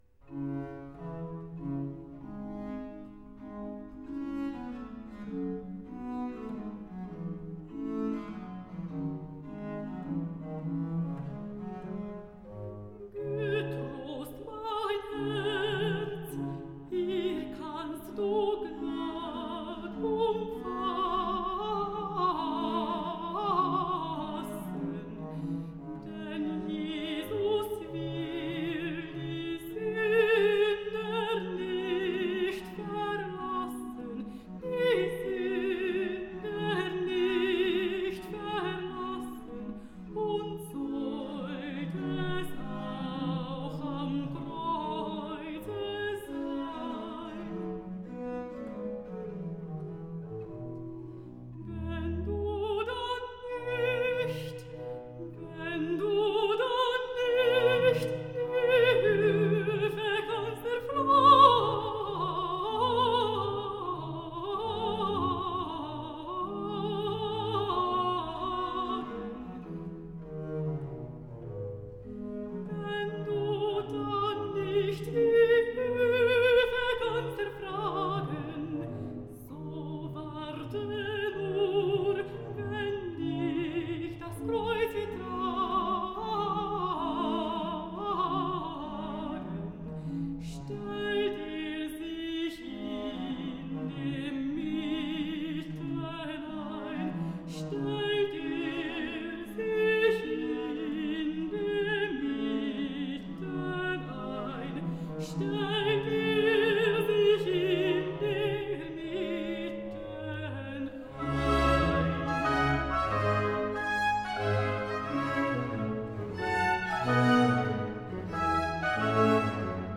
Aria alto